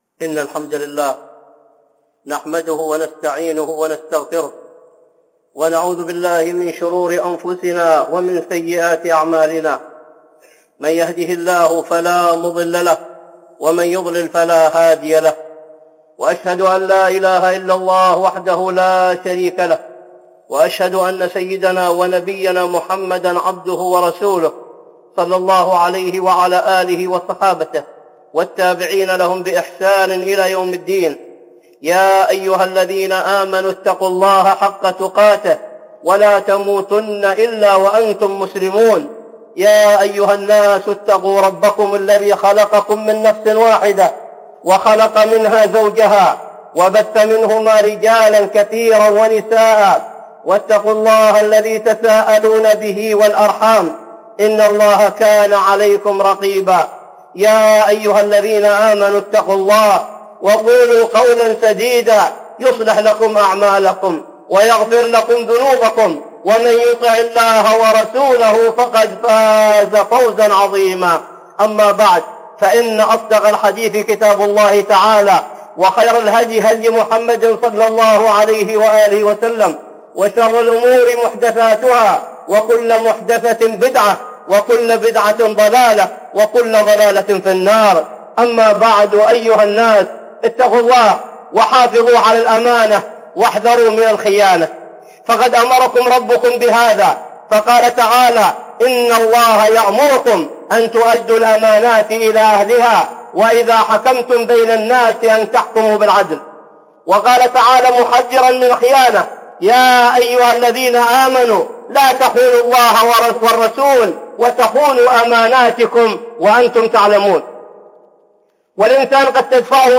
(خطبة جمعة) الخونه والغدارون